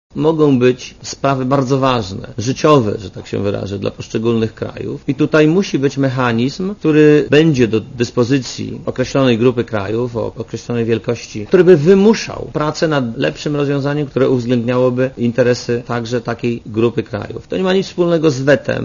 W odróżnieniu od poprzednich sesji negocjacyjnych, Polska nie była jedynym państwem, które opowiedziało się za odwołaniem do tradycji chrześcijańskich w preambule Traktatu Konstytucyjnego UE - powiedział premier Marek Belka po pierwszej sesji roboczej Rady Europejskiej.
Posłuchaj premiera Belki (74kB)